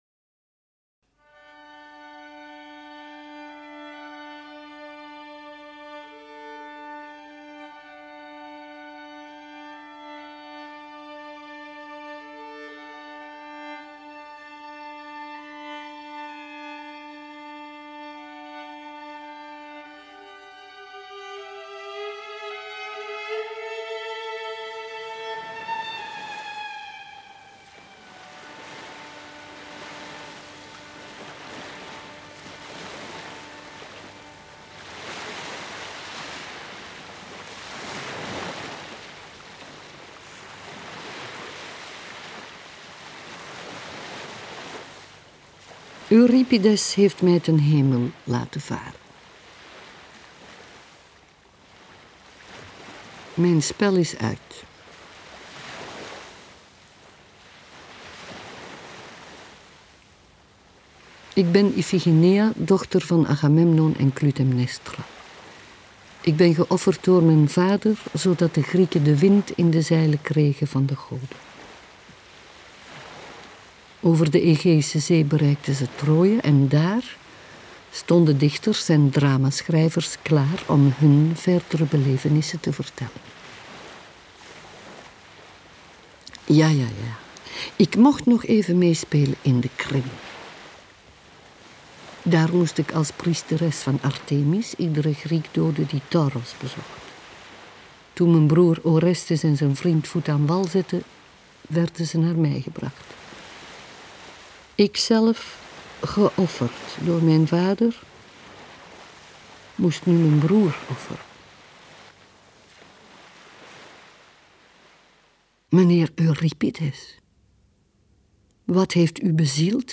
Hier, in dit radiodrama uit 1987 neemt Ifigeneia het op tegen de Griekse auteur. Ze vertelt het verhaal vanuit haar persoonlijk standpunt. Dora Van der Groen speelt de rol van Ifigeneia. Ze vertelt haar versie met de afstandelijke intensiteit haar eigen.